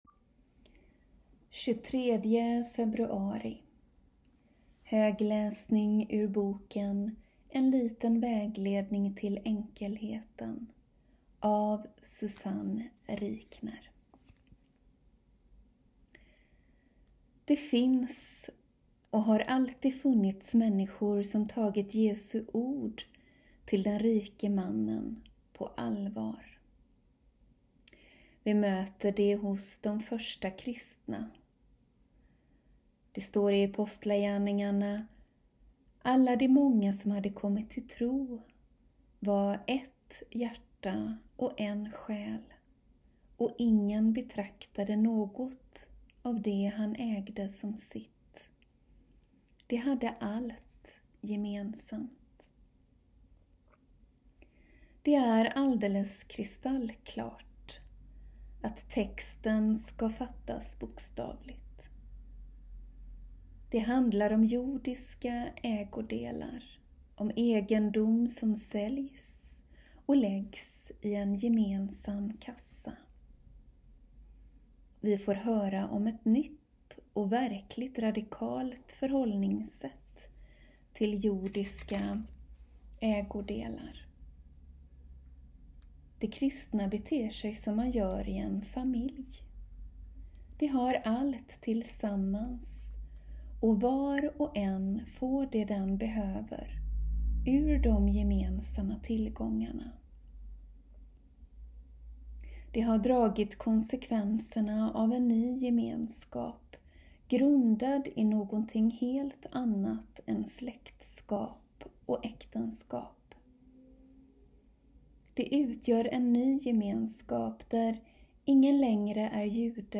Daglig läsning från klostret ur boken En liten vägledning till enkelheten av Susanne Rikner